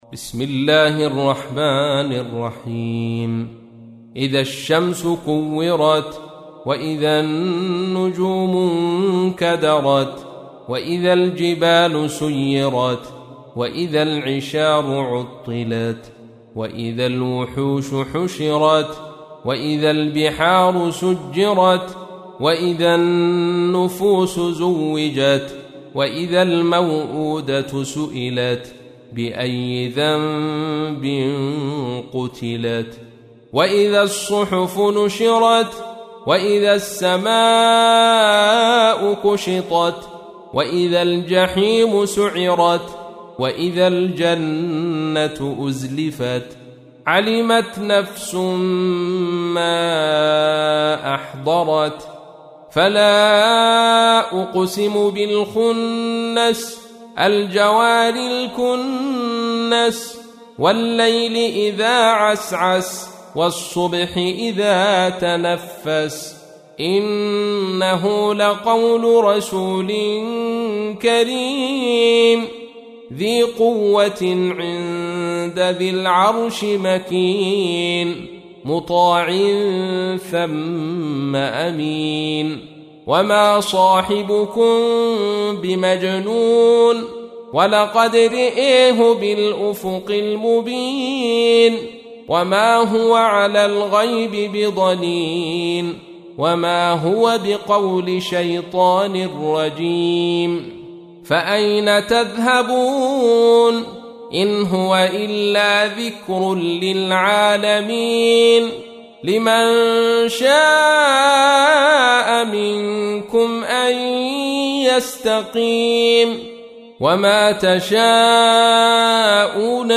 تحميل : 81. سورة التكوير / القارئ عبد الرشيد صوفي / القرآن الكريم / موقع يا حسين